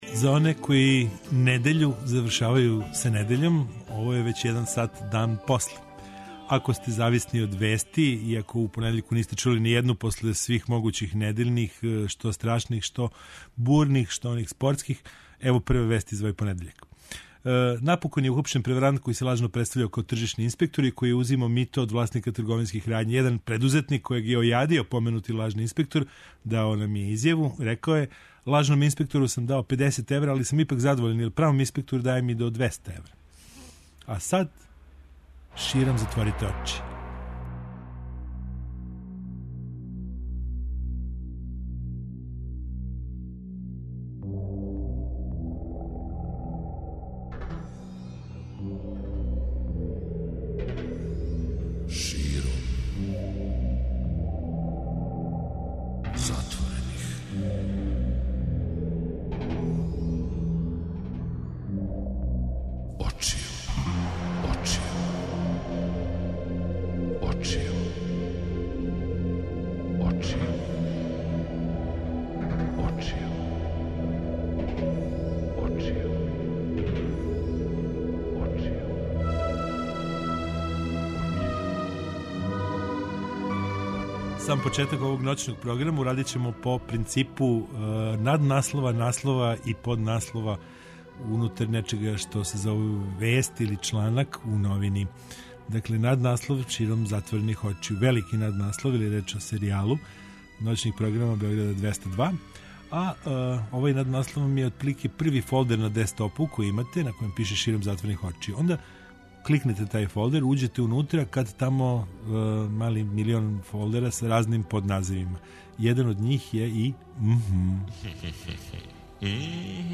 Ноћни програм Београда 202